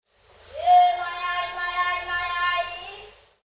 The low background noises of the morning are pierced each day around 7 a.m. by the shrill cry of a woman who seems to be complaining bitterly about her eye.